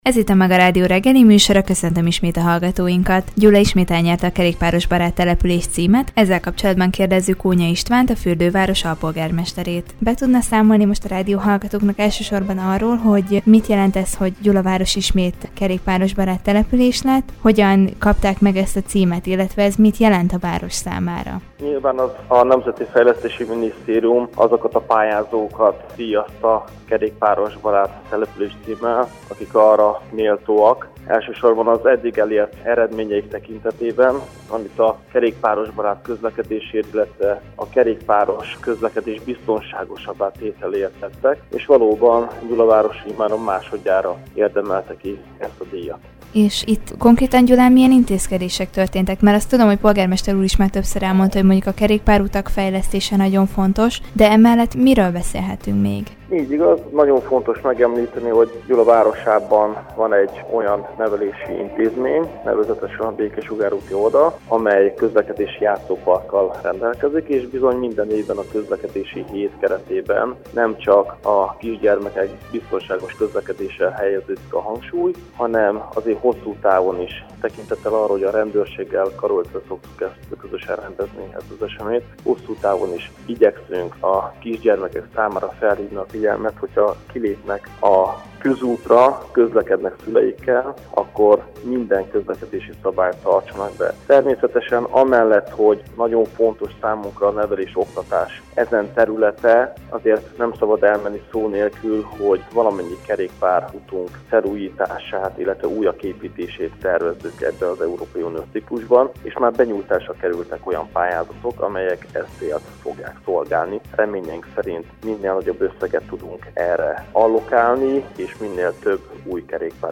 Gyula másodjára nyerte el a Kerékpárosbarát Település címet, ehhez kapcsolódva kérdeztük Kónya István alpolgármestert, a várost érintő kerékpáros fejlesztésekről.